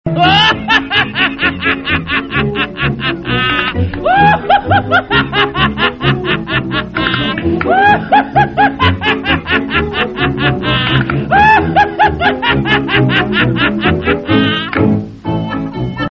ضحكة من الطراز الاول – نغمة كوميديه